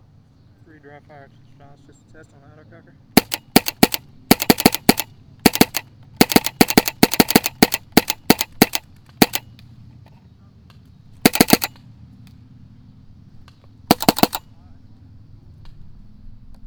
autococker_dry_raw_clipped02.wav